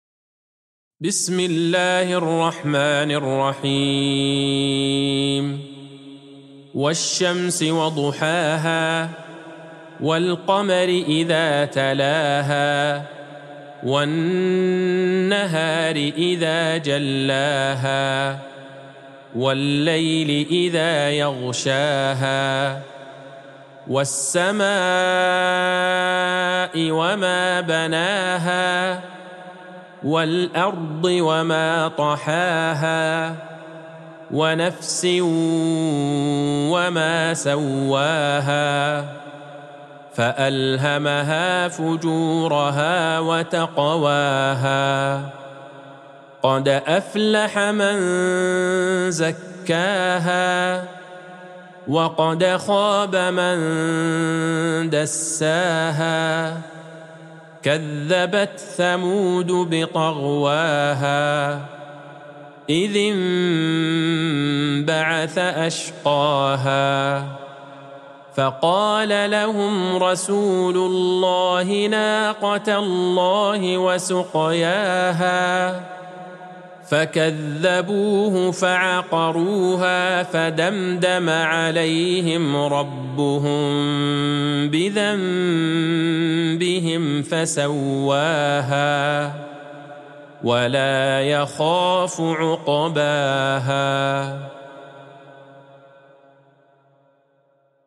سورة الشمس Surat Ash-Shams | مصحف المقارئ القرآنية > الختمة المرتلة ( مصحف المقارئ القرآنية) للشيخ عبدالله البعيجان > المصحف - تلاوات الحرمين